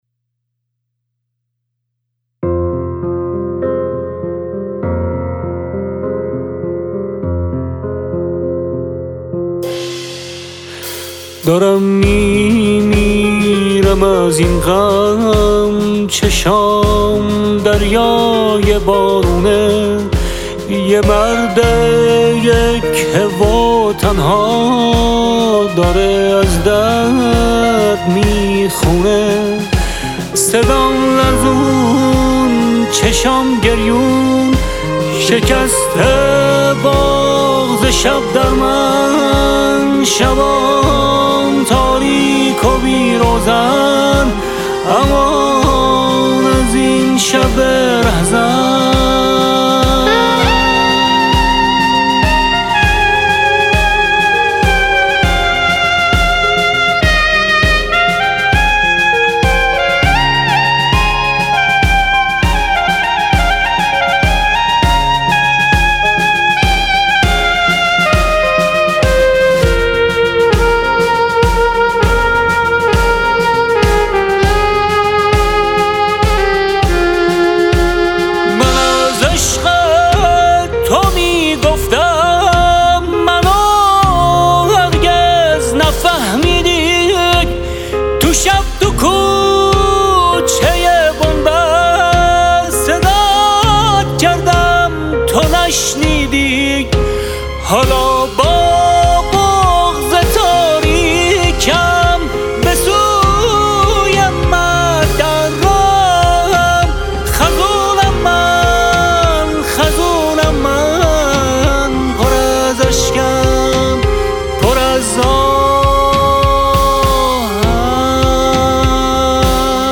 ساکسیفون سوپرانو: